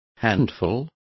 Complete with pronunciation of the translation of handful.